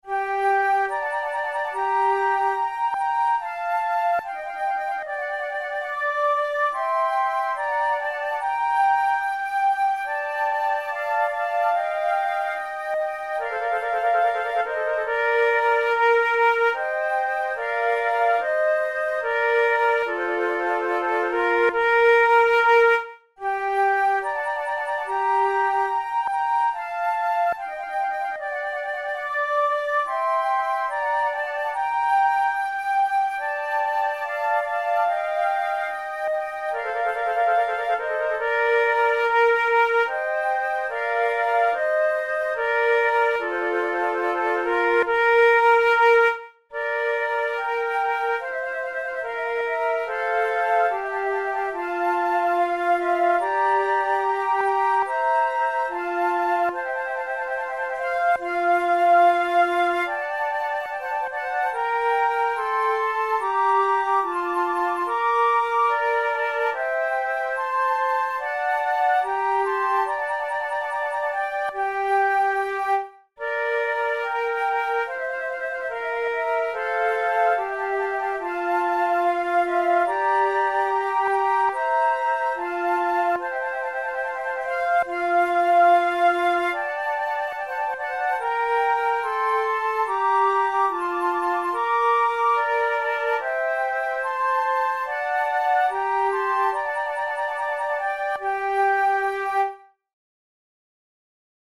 InstrumentationFlute trio
KeyG minor
Time signature4/4
Tempo72 BPM
Baroque, Sonatas, Written for Flute